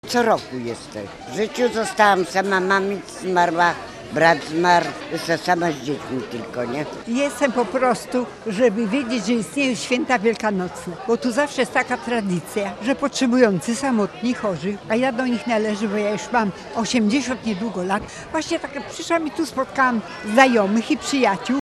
Na placu obok bazyliki św. Mikołaja w Gdańsku odbyło się spotkanie wielkanocne dla osób potrzebujących, samotnych i w kryzysie bezdomności.
W spotkaniu wzięło udział ponad 300 osób. Posłuchaj głosu uczestników: https